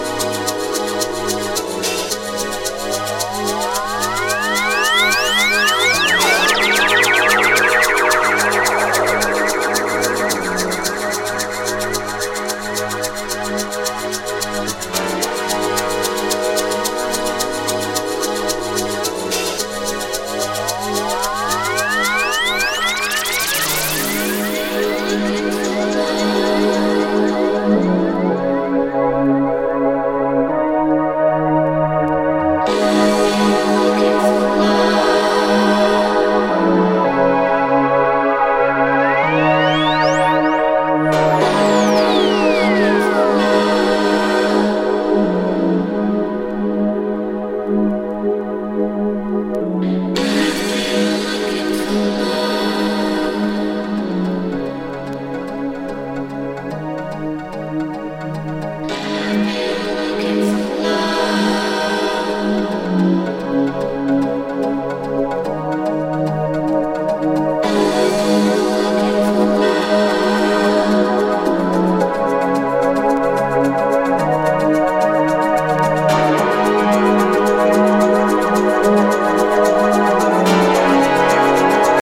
ハウステンポながらもキックレスで展開していくB-2もお忘れなく。